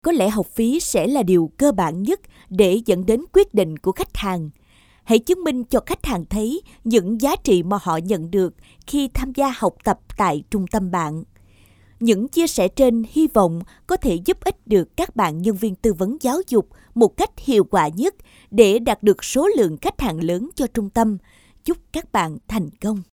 女越南1T-4 越南语女声 低沉|激情激昂|大气浑厚磁性|沉稳|娓娓道来|科技感|积极向上|时尚活力|神秘性感|素人